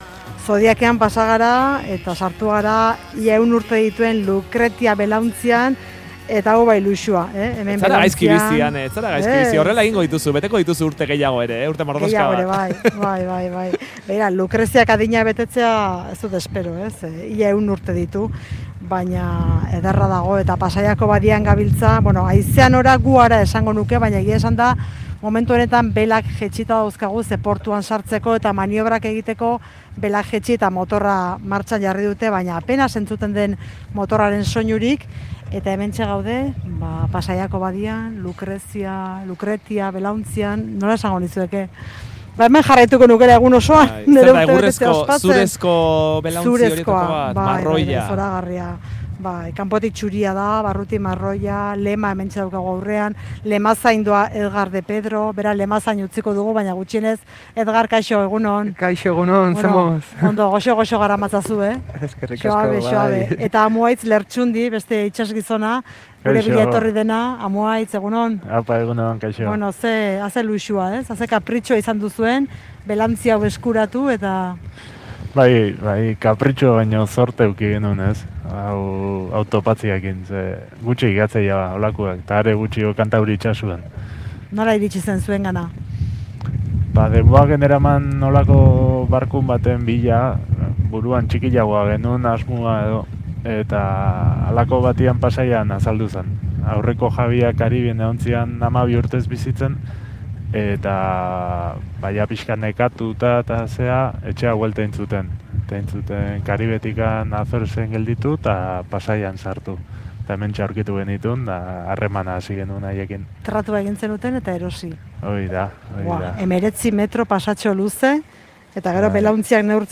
Audioa: Lucretia belaontzian ibili gara Pasaiako badian